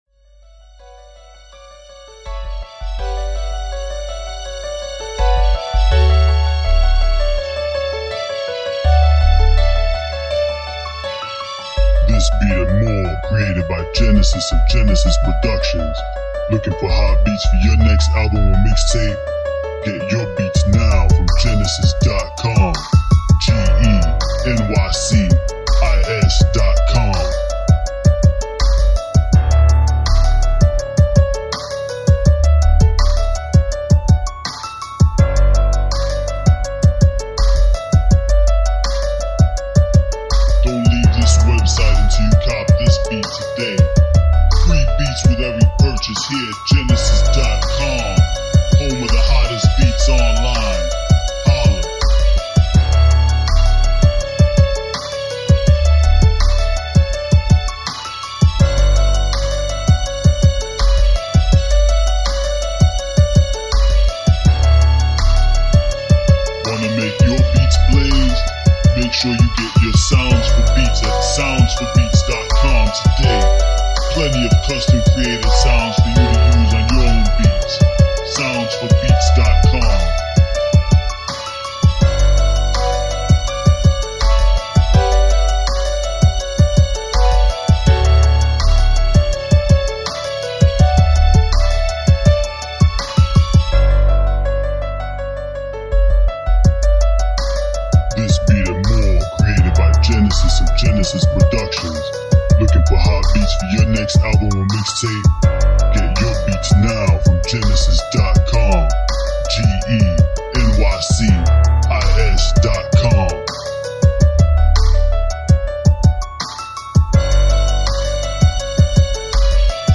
Storytelling Beats